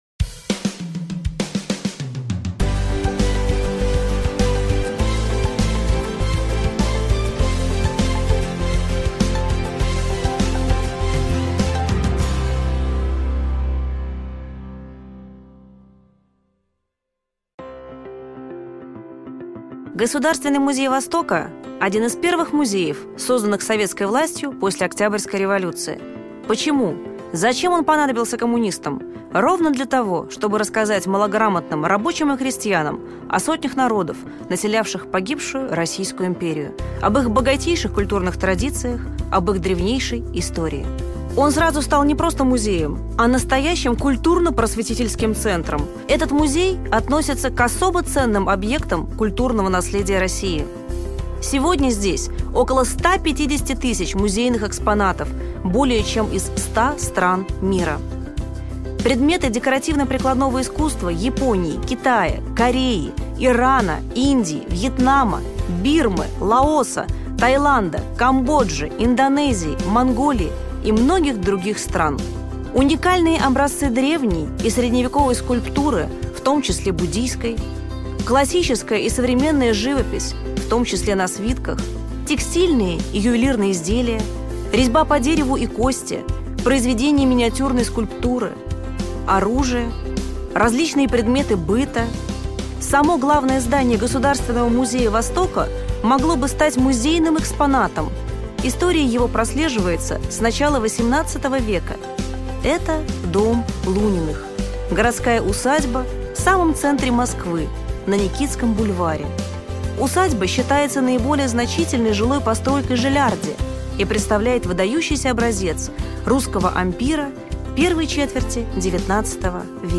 Аудиокнига Вечное противостояние: Европа и национализм в Китае | Библиотека аудиокниг